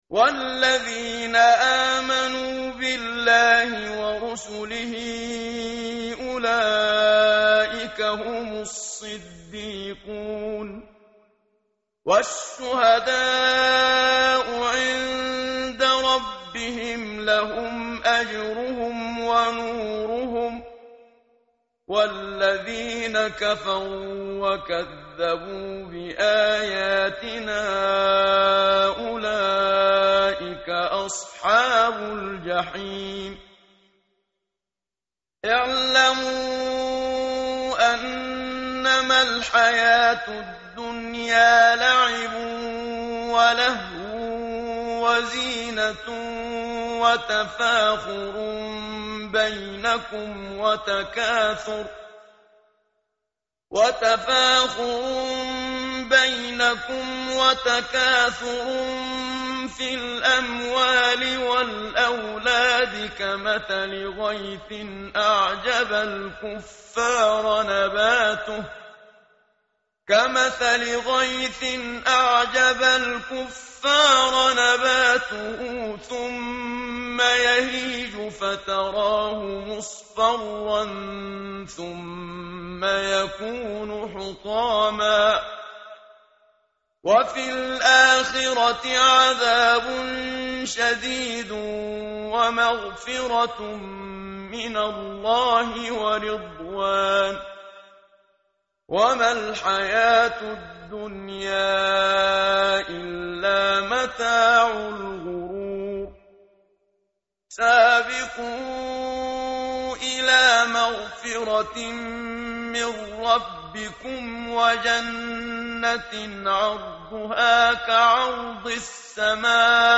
tartil_menshavi_page_540.mp3